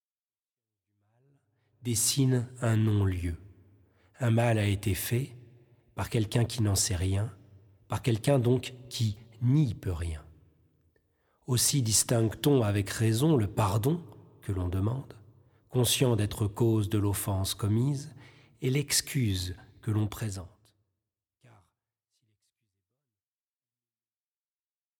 méditations spirituelles